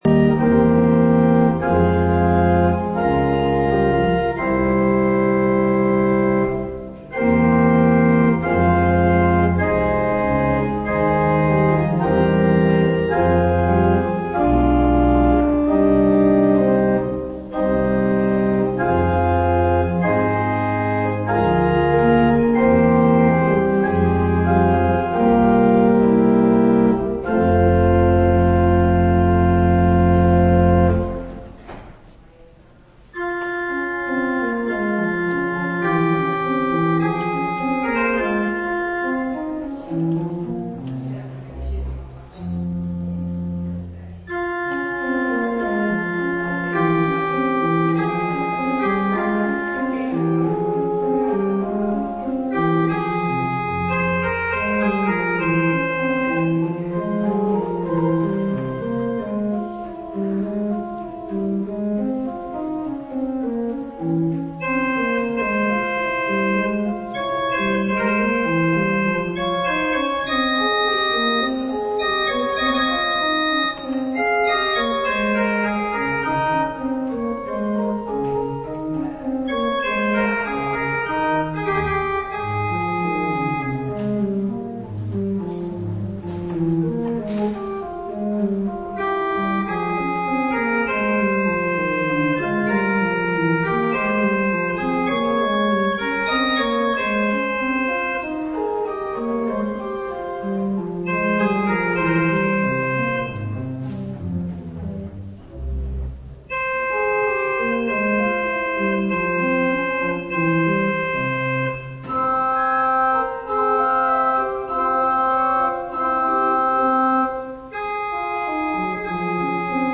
Preek over Jozua 7 op zondagmorgen 10 februari 2019 - Pauluskerk Gouda